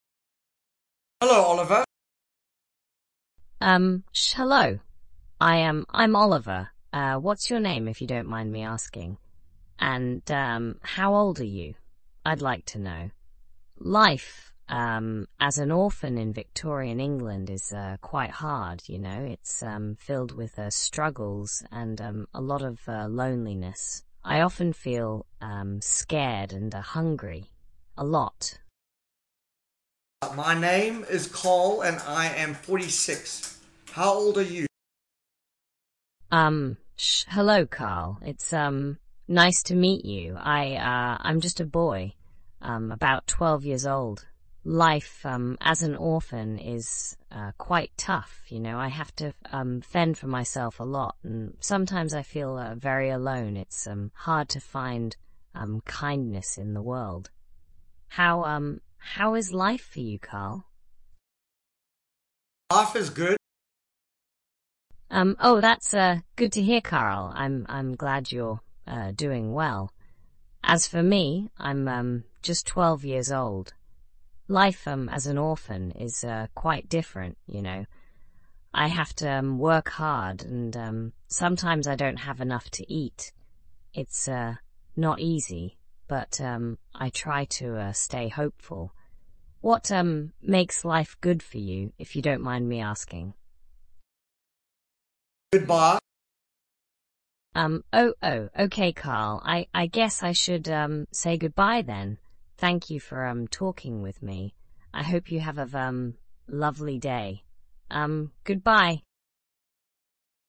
AI Examples / Voice ChatBots with Persona...